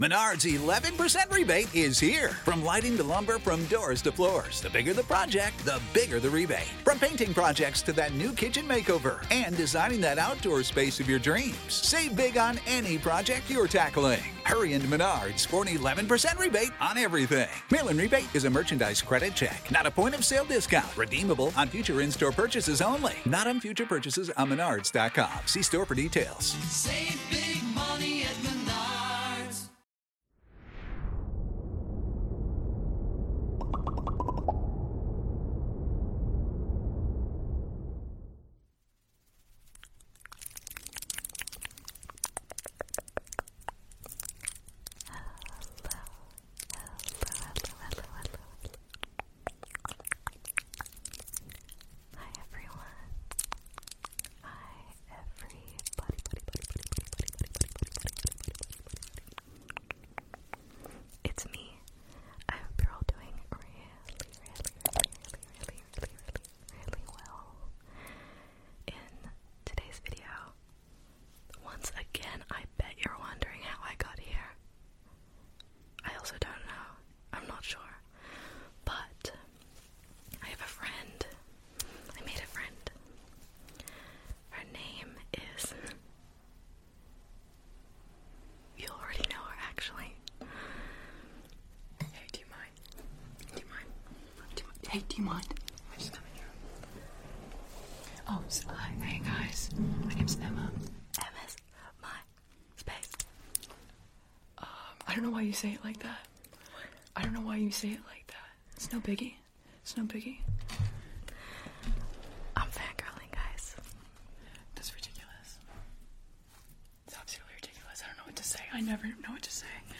ASMR Podcast Nighttime unfiltered asmr (ft.